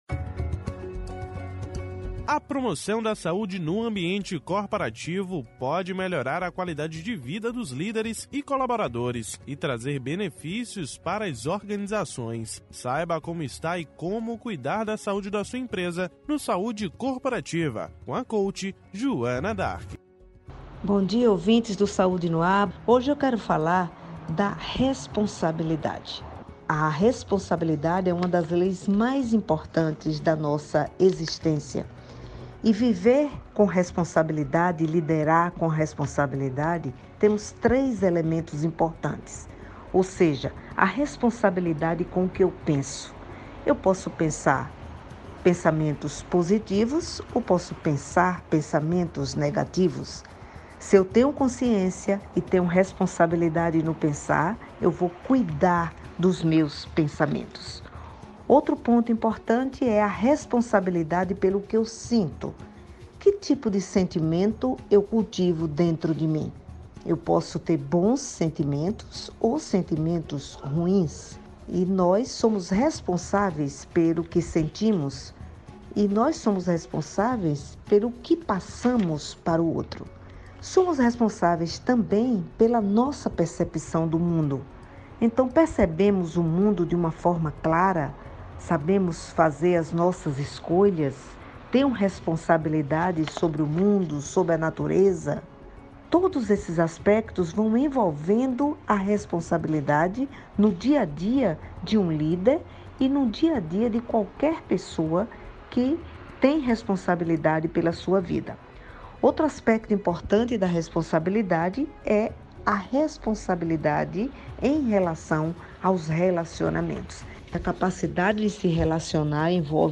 O assunto foi tema do Quadro “Saúde Corporativa” desta segunda-feira (30/10), no Programa Saúde no Ar.